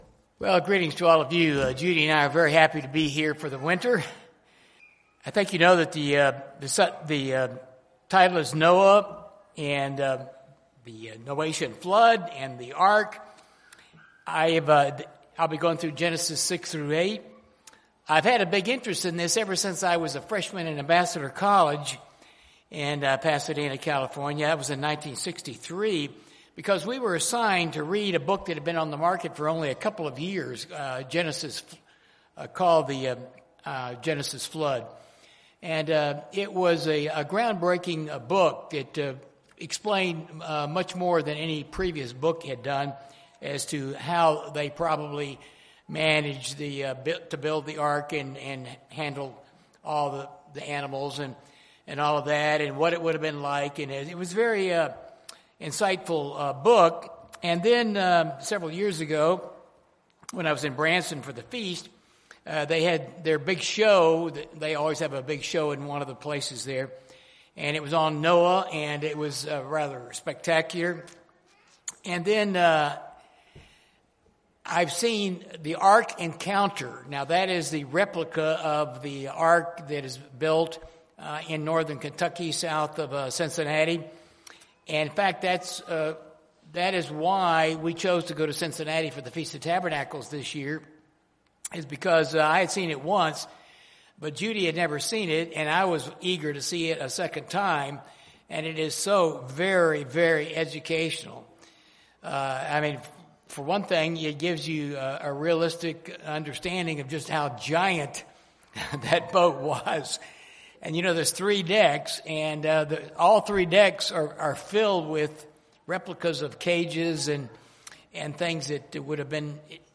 The details described in Genesis 6-9 are fascinating and some of them are misunderstood and some are controversial. This sermon explains all those details plus the spiritual lessons related in the New Testament.